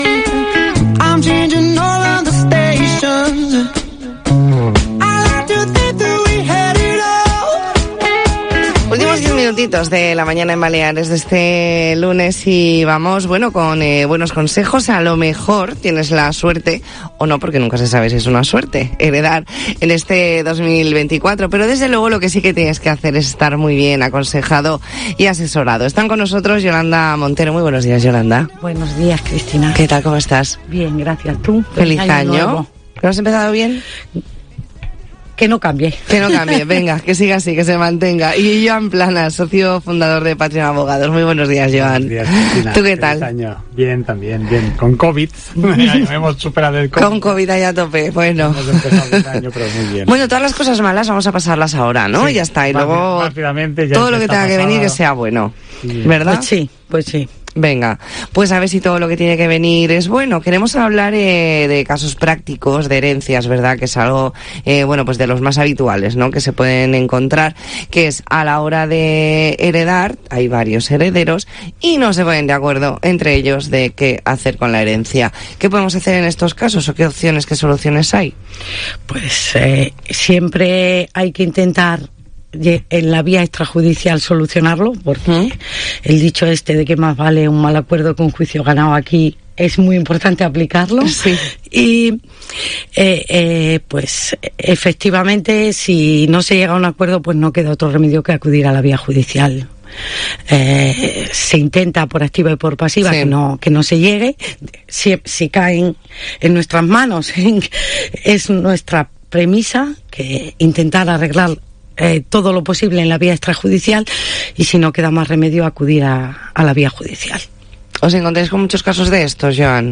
ntrevista en La Mañana en COPE Más Mallorca, lunes 8 de enero de 2024.